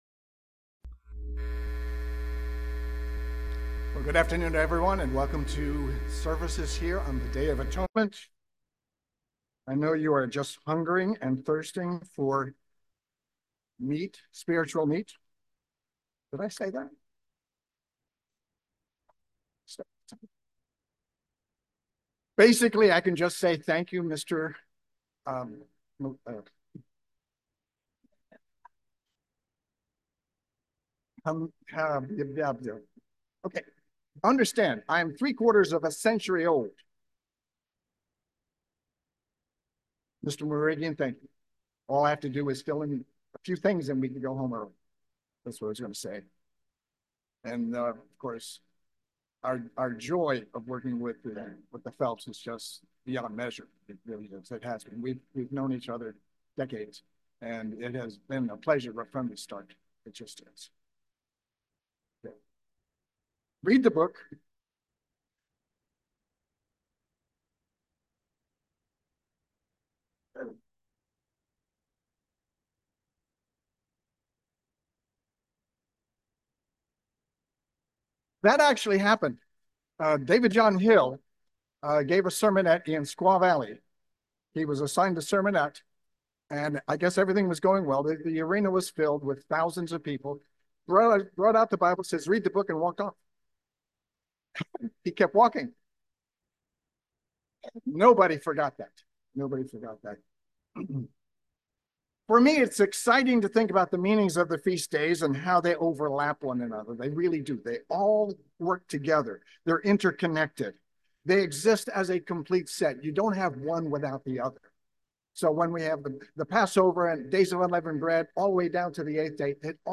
Sermons
Given in Petaluma, CA